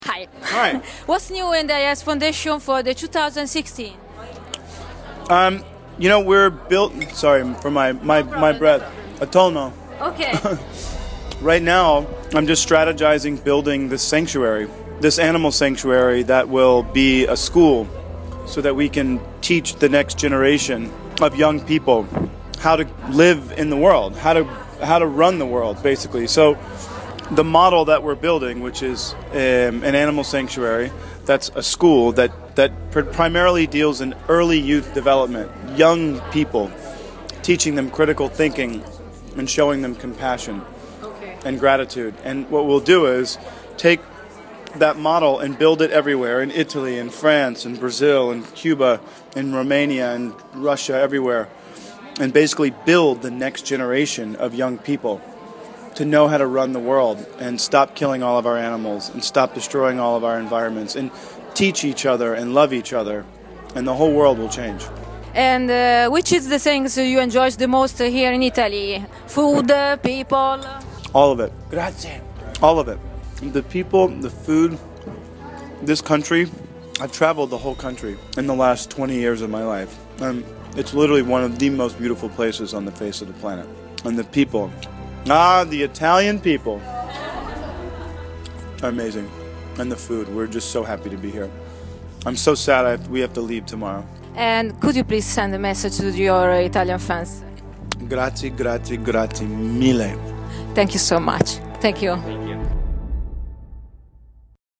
Ian_Somerhalder_interview_Rome_2015.mp3